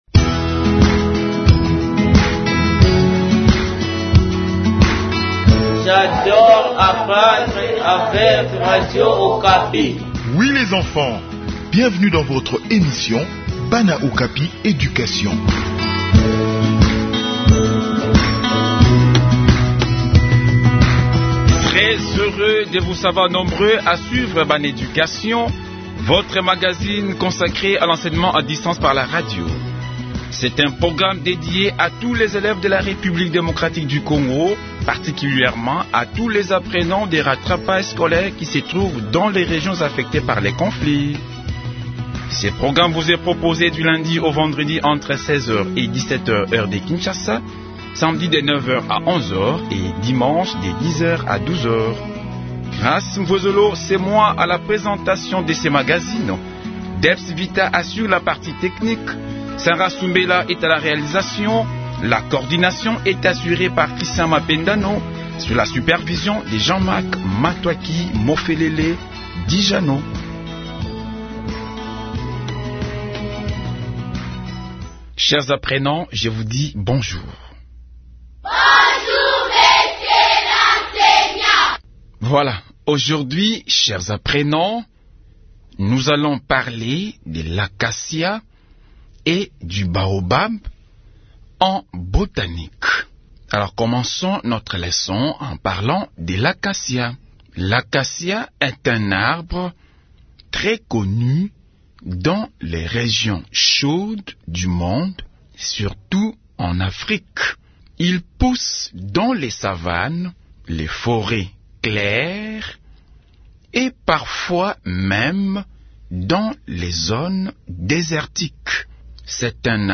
Enseignement à distance : tout savoir sur l'acacia et le baobab